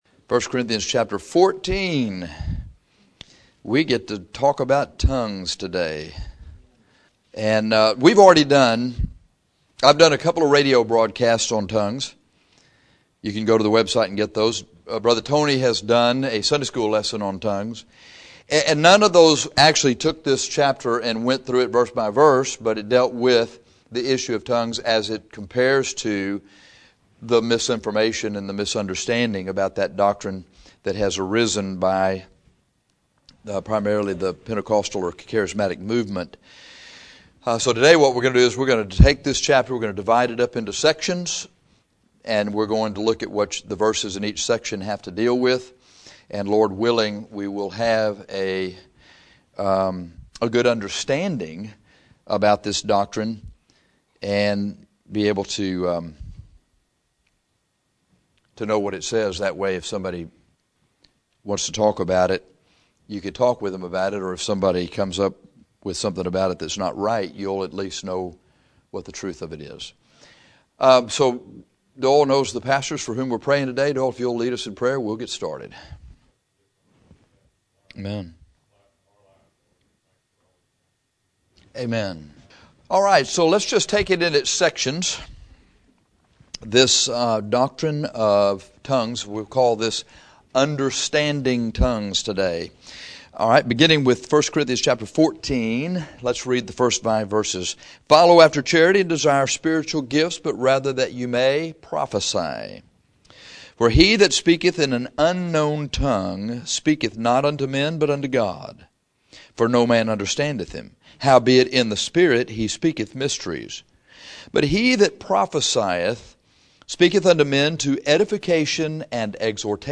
In this Sunday school lesson we will go through 1 Cor 14, subdividing the chapter into sections and dealing with each verse in each section, so that you can get an understanding of this often misunderstood doctrine.